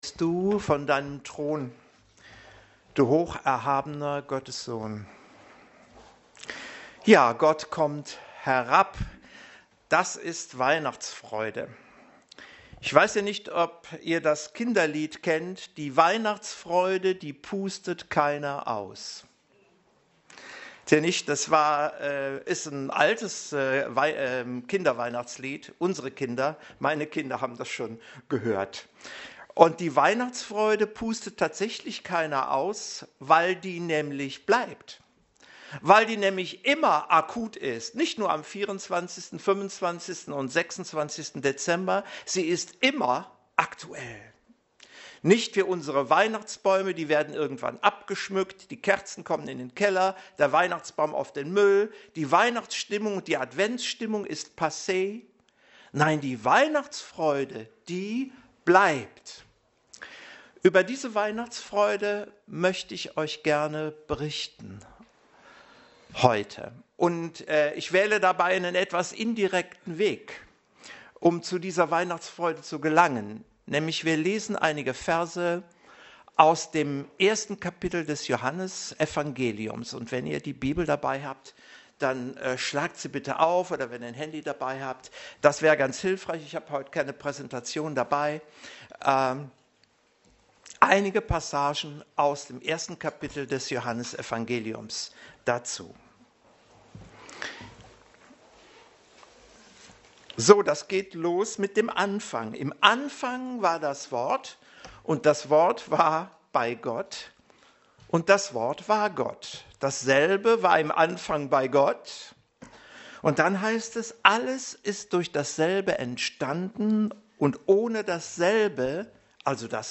Passage: Johannes 1,1-14 Dienstart: Predigt